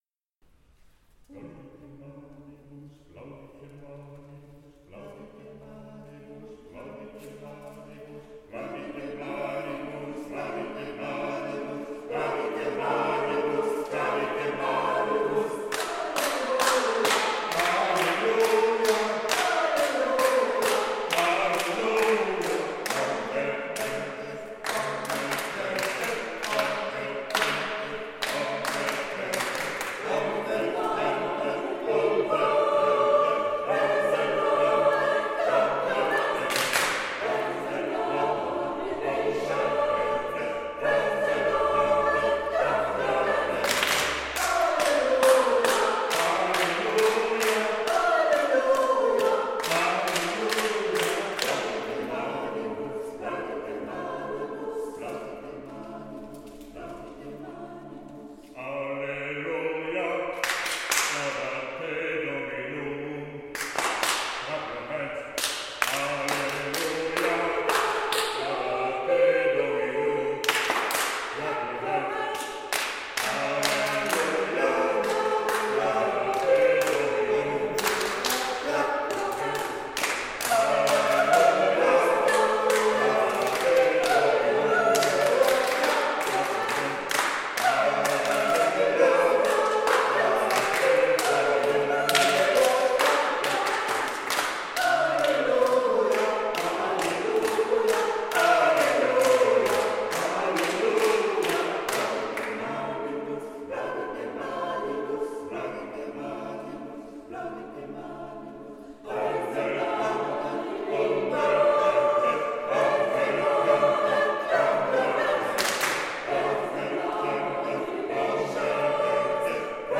Musique chorale américaine
Extraits de la restitution de fin de stage dans l'église de Sainte Thumette à Penmarc'h, le 3 mai 2024